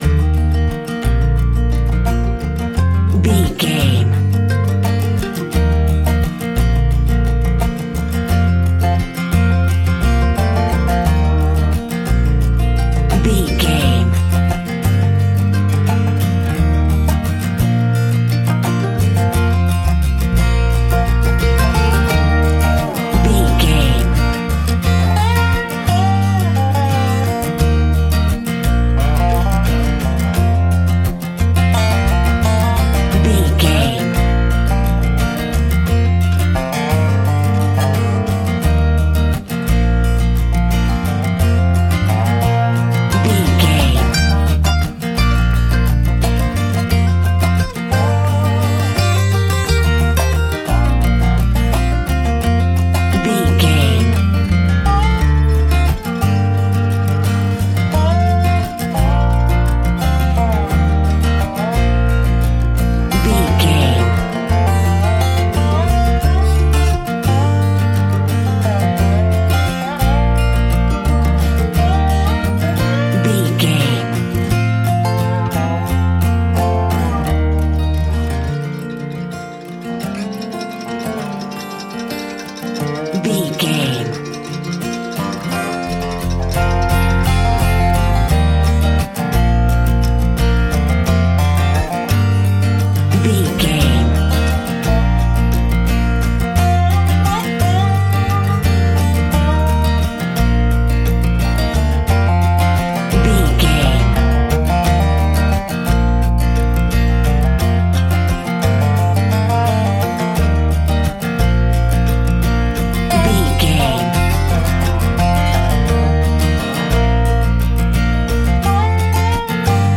Uplifting
Ionian/Major
B♭
acoustic guitar
bass guitar
banjo